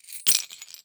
Coins_Bottlecaps_Drop.wav